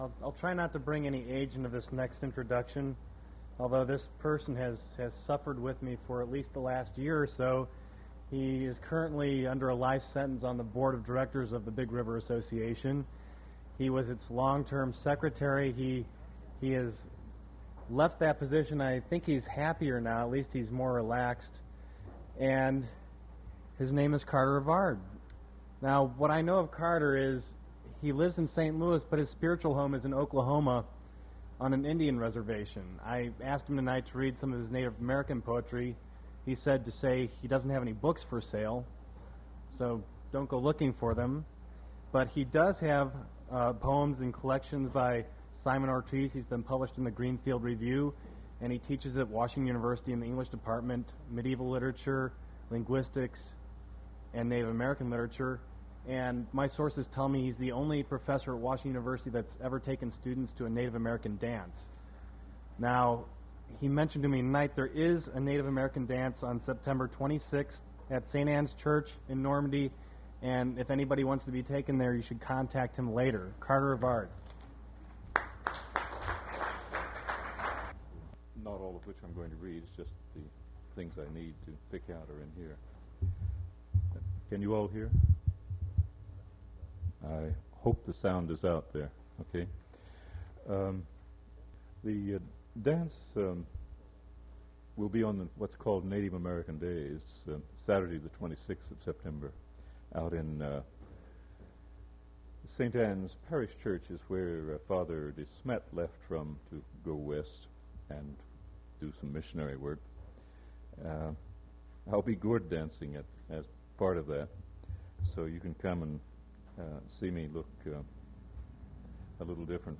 Poetry reading featuring Carter Revard
Attributes Attribute Name Values Description Carter Revard poetry reading at Duff's Restaurant.
mp3 edited access file was created from unedited access file which was sourced from preservation WAV file that was generated from original audio cassette.